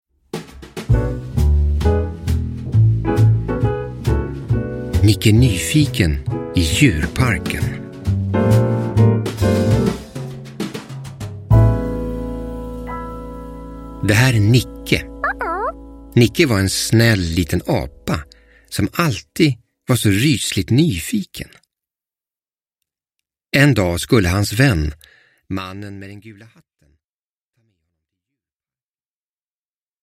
Nicke Nyfiken i djurparken – Ljudbok – Laddas ner